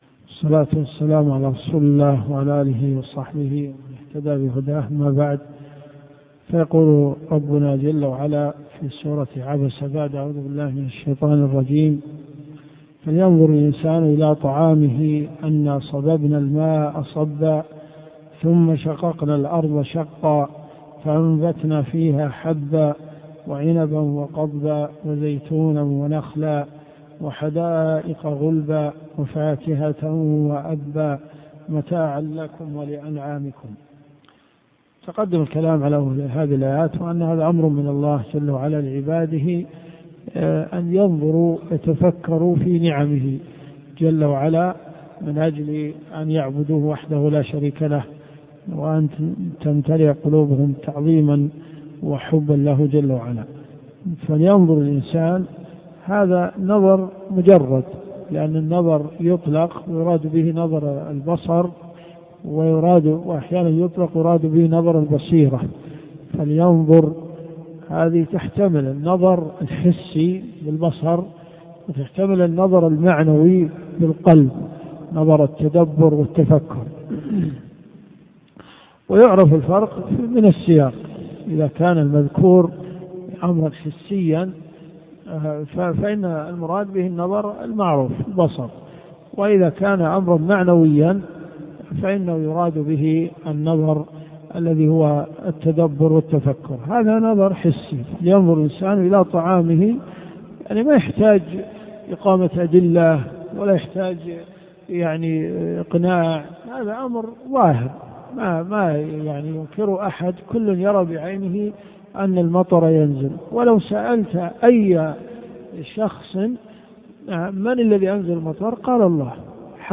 تفسير القران الكريم